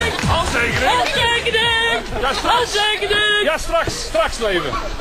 handtekening-kukelekuuuhanenvrouw-audiotrimmer.mp3